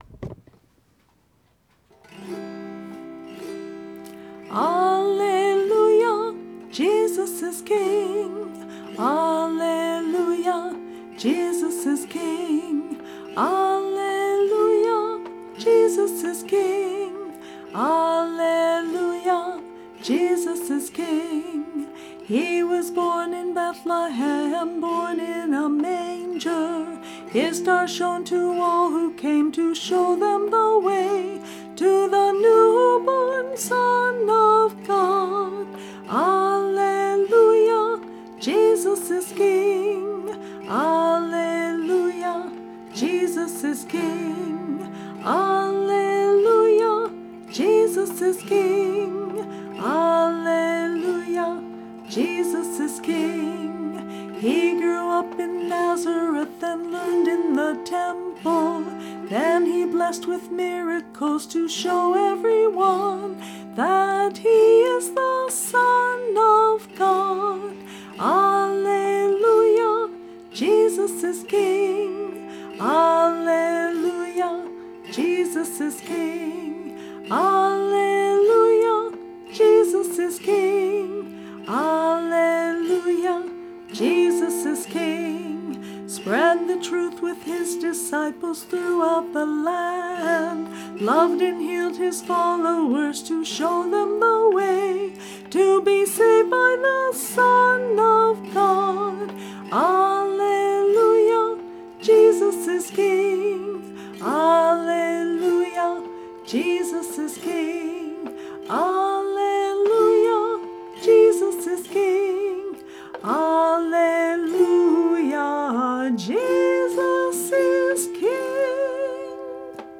This entry was posted in Christian Music, Uncategorized and tagged , , , , , , , , , .